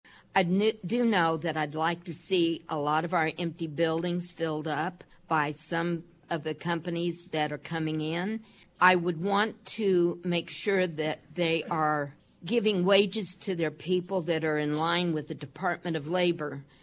During this past weekend’s League of Women Voters Forum on KMAN, candidates were asked what the city’s role should be in spurring development of jobs that provide a livable wage.
Seven took part in the forum Saturday at the Manhattan Public Library, hosted by the League and co-sponsored by the local chapter of the American Association of University Women and the Manhattan Area Chamber of Commerce.